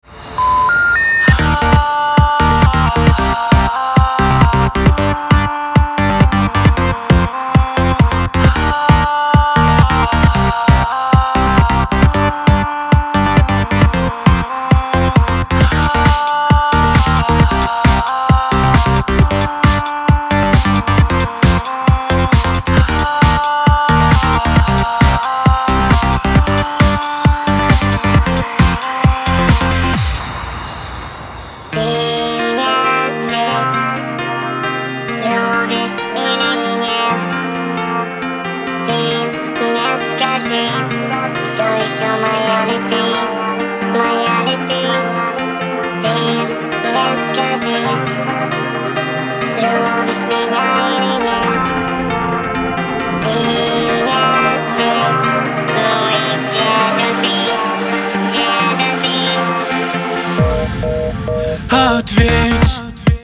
• Качество: 256, Stereo
dance
electro